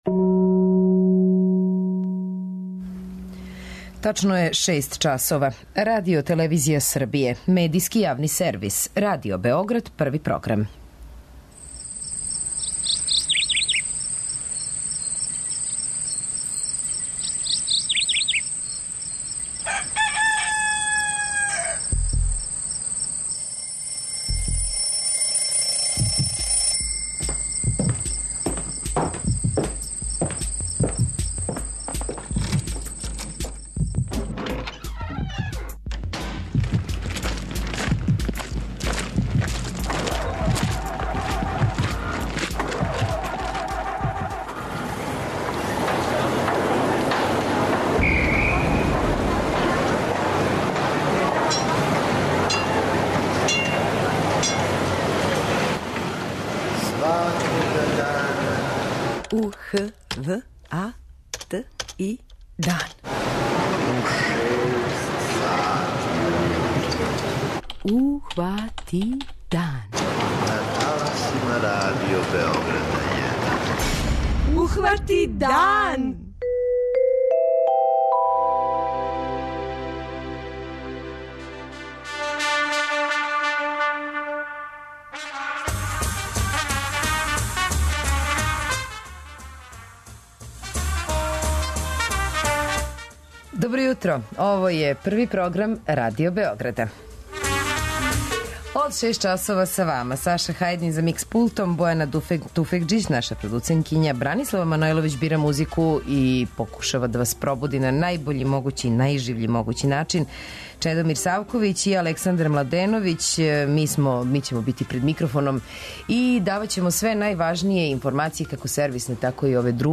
преузми : 85.93 MB Ухвати дан Autor: Група аутора Јутарњи програм Радио Београда 1!